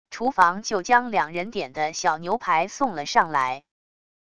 厨房就将两人点的小牛排送了上来wav音频生成系统WAV Audio Player